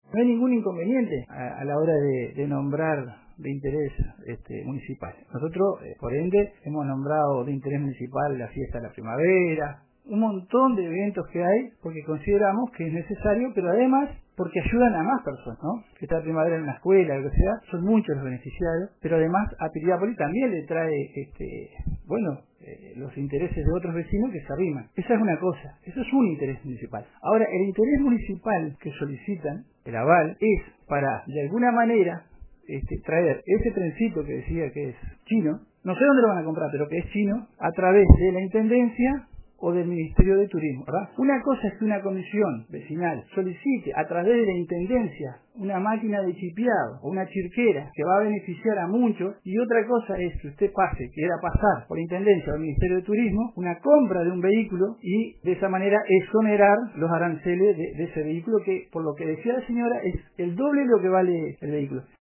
El viernes, en el informativo Central de RADIO RBC, el Concejal Alberto Miranda, se refirió a un proyecto de un tren con ruedas de calle, presentado ante el Concejo del Municipio de Piriápolis, y dijo que para aprobarlo había que cumplir el protocolo correcto que era hacer un llamado público, por si alguien más se quería presentar.